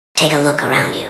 Cartoon Run Take Off